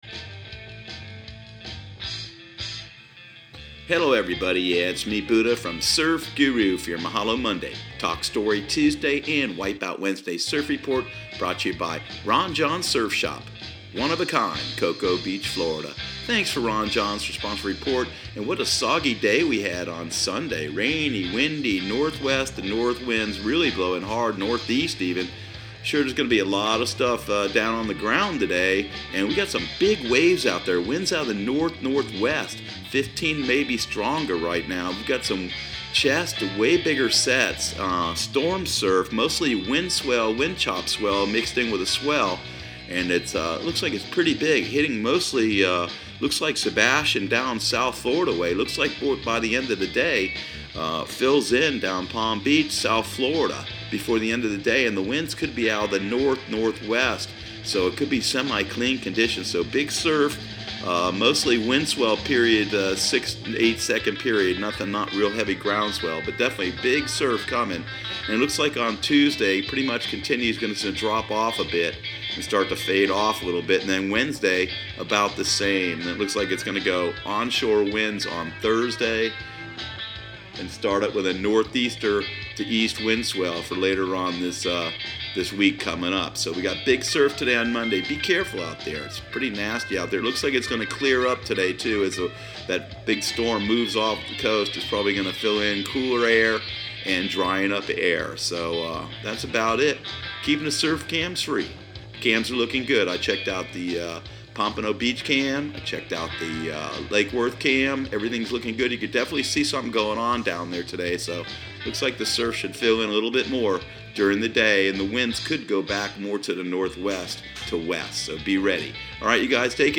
Surf Guru Surf Report and Forecast 01/28/2019 Audio surf report and surf forecast on January 28 for Central Florida and the Southeast.